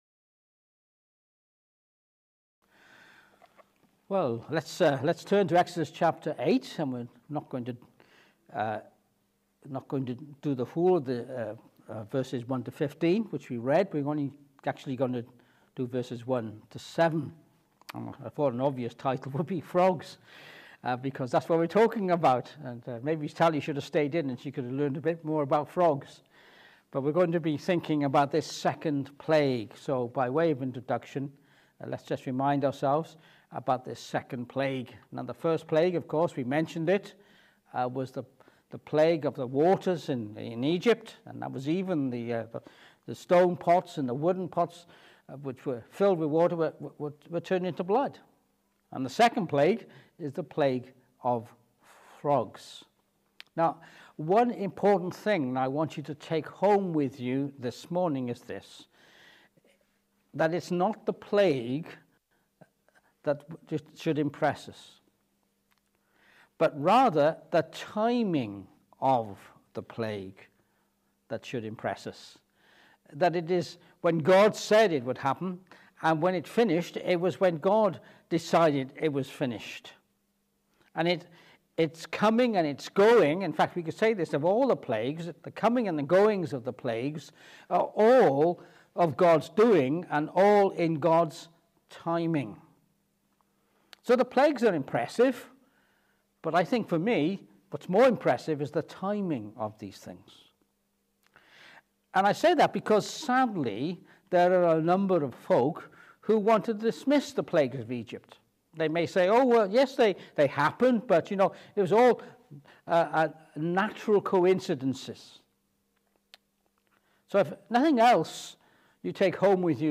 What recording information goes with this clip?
Exodus 8:1-15 Service Type: Morning Service This morning we continue our series in Exodus.